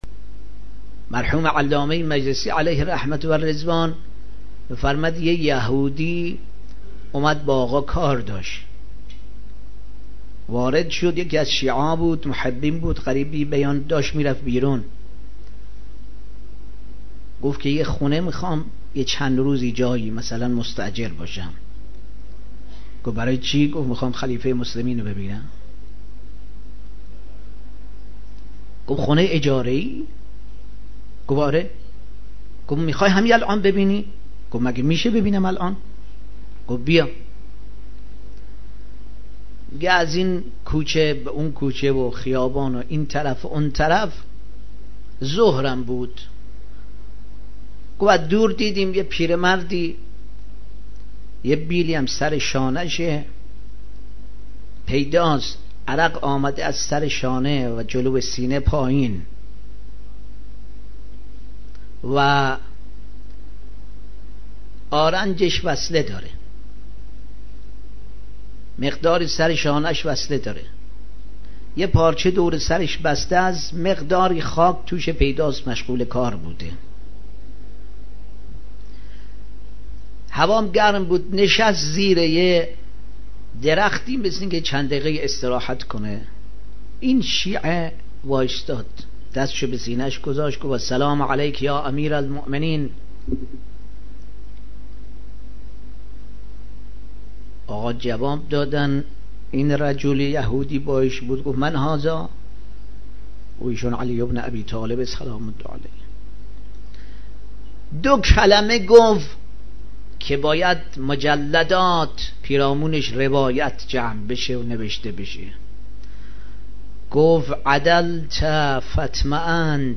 در یکی از سخنرانی‌هایش